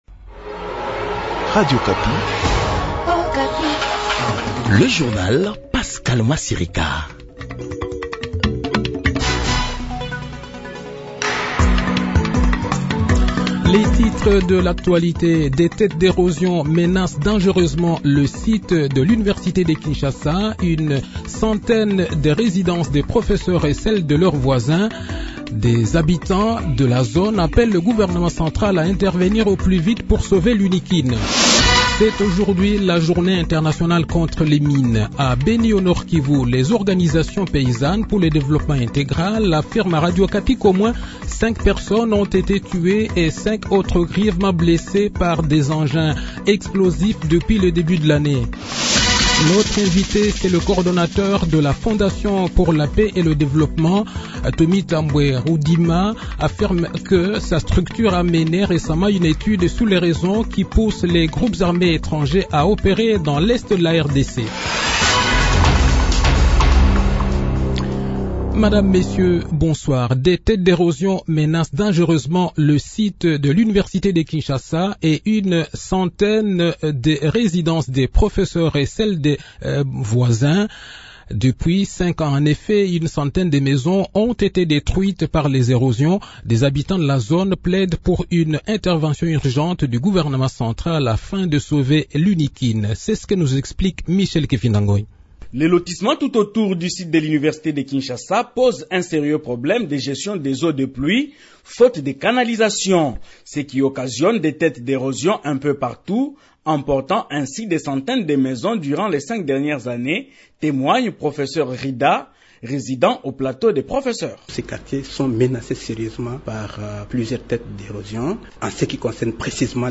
Le journal de 18 h, 4 avril 2024